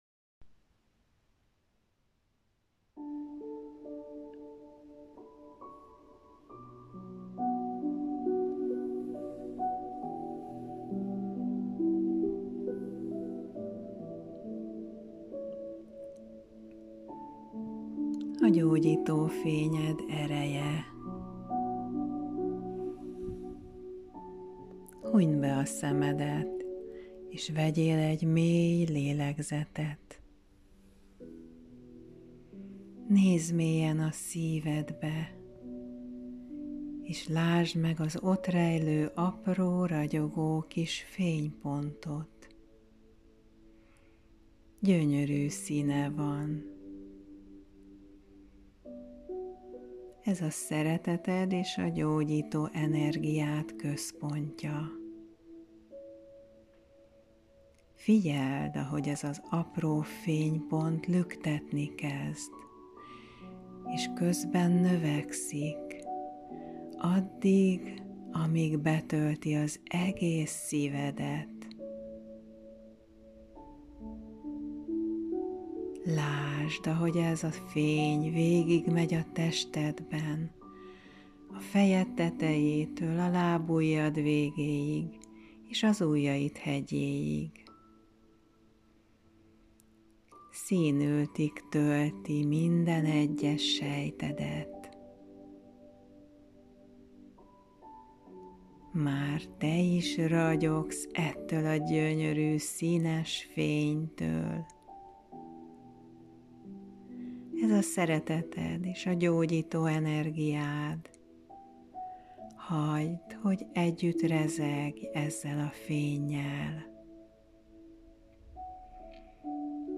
Szeretettel ajánlom ezt a rövid vizualizációs gyakorlatot elméd elcsendesítésére és tested-lelked feltöltésére. Ha van kedved és lehetőséged, helyezd magad kényelembe és már kezdhetünk is…